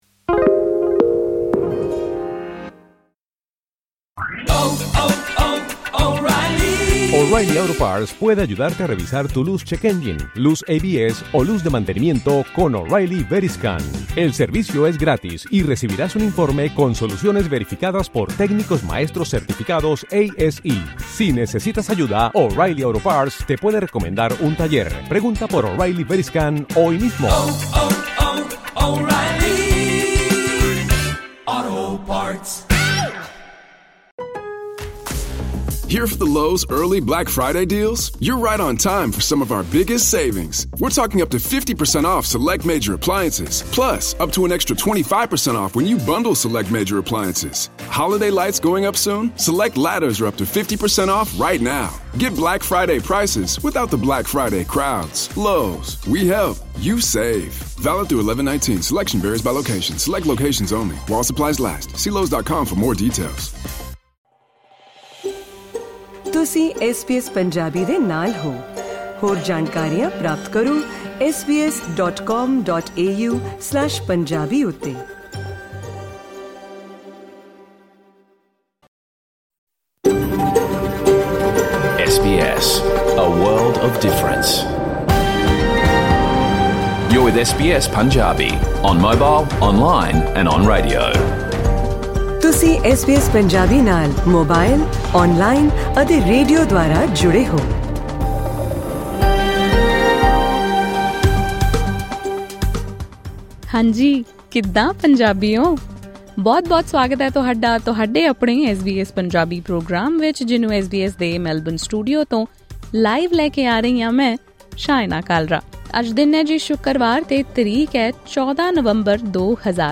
ਸੁਣੋ ਐਸ ਬੀ ਐਸ ਪੰਜਾਬੀ ਦਾ ਪੂਰਾ ਰੇਡੀਓ ਪ੍ਰੋਗਰਾਮ